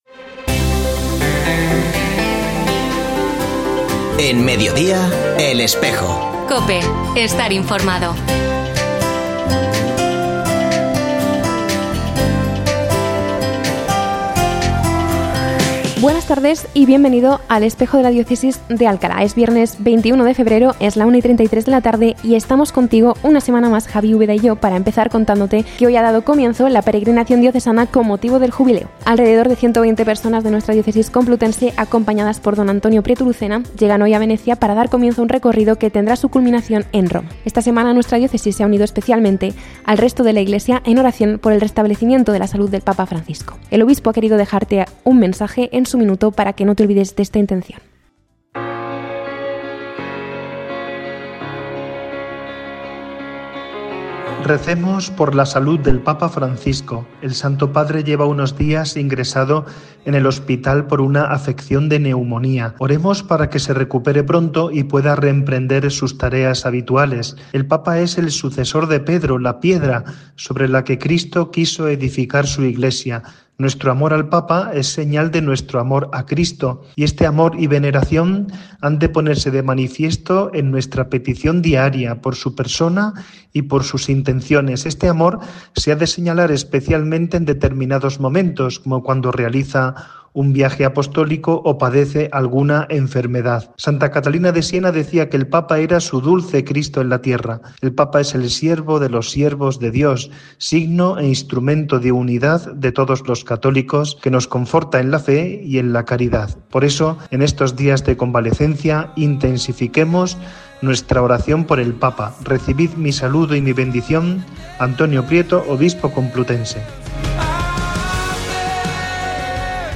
Ya se ha emitido hoy, 21 de febrero de 2025, el nuevo programa de El Espejo de la Diócesis de Alcalá en la emisora de radio COPE. Este espacio de información religiosa de nuestra diócesis puede escucharse en la frecuencia 92.0 FM, todos los viernes de 13.33 a 14 horas.
En el programa de hoy escuchamos tres testimonios de matrimonios que hicieron la ruta romántica el pasado sábado 15 de febrero, como parte de la Semana del Matrimonio.